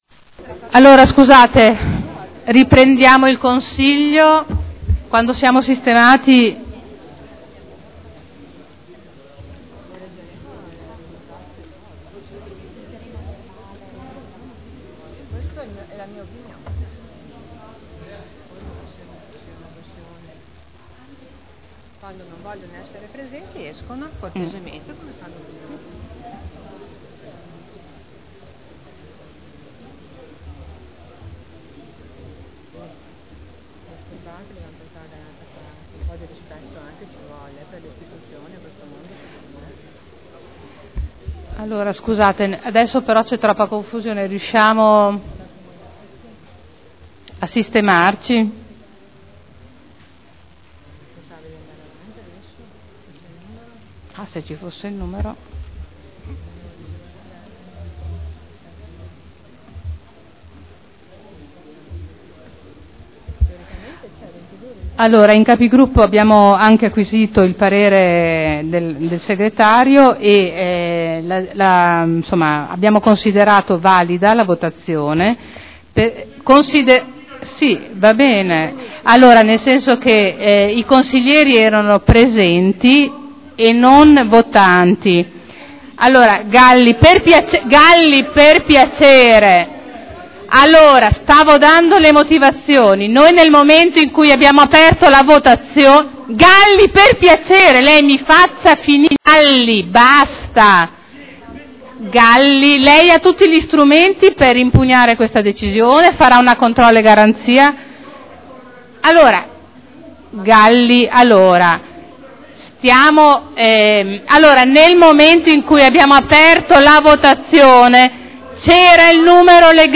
Seduta del 12/09/2011. Riprendono i lavori dopo la sospensione per la riunione dei Capi Gruppo.